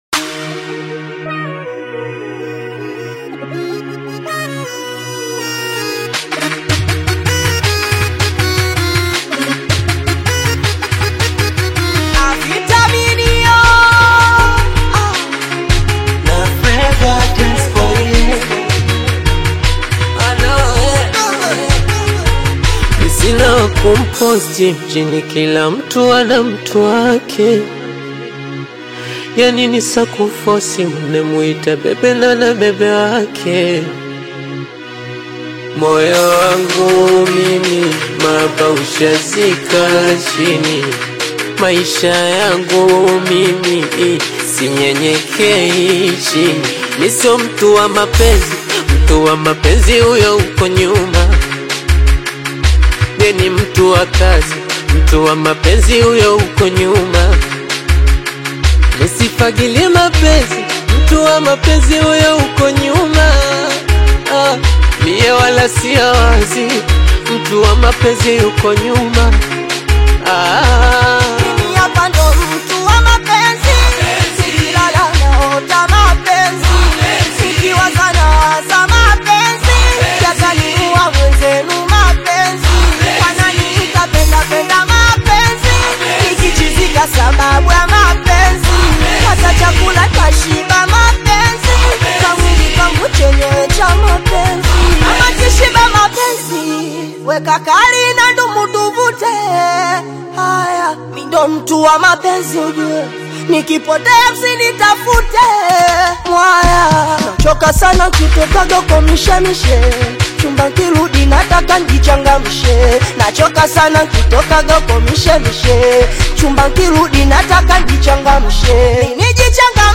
AudioSingeli
Bongo Flava/Afro-Fusion single